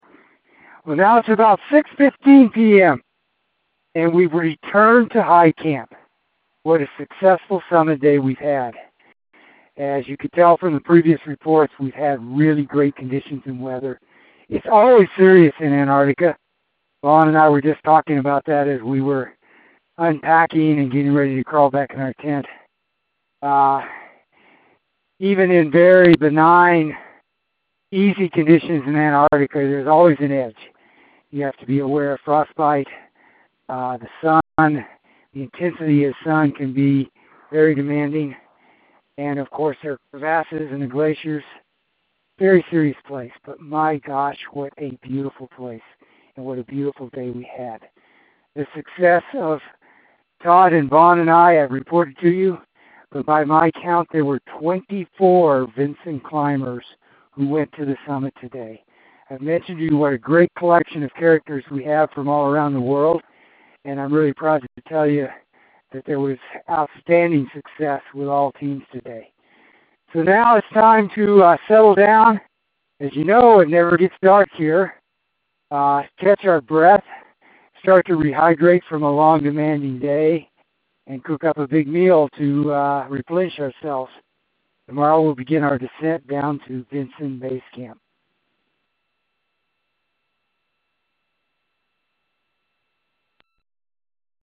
Vinson Dispatch: December 5, 2012 – Returned to High Camp
Expedition Dispatch